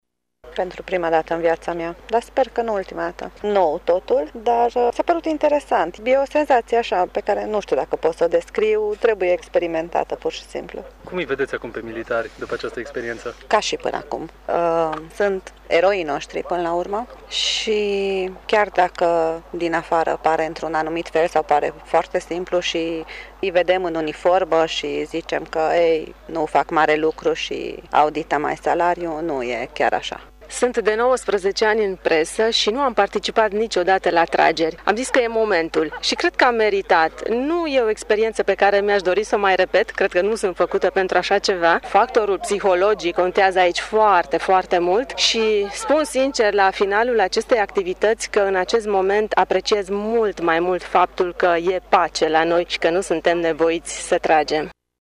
În Poligonul de tragere din Sângeorgiu de Mureș a fost organizată„Cupa Presei la Tir” , parte a manifestărilor care marchează Ziua Armatei Române din 25 Octombrie.
Trei din cei 15 reprezentanți ai presei mureșene au concurat astăzi pentru prima dată: